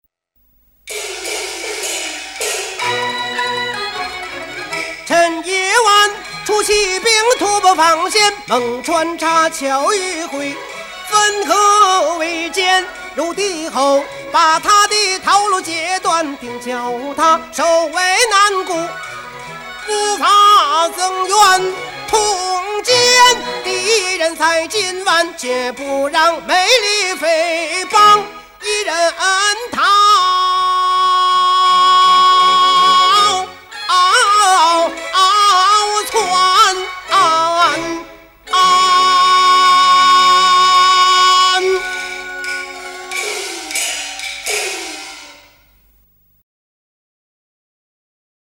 【二黄快板】
而录制中扩大为86331，即第一与第二小提琴各增加了一倍；中提与大提也各增加到了三把，从而使音响效果更为浑厚而丰满。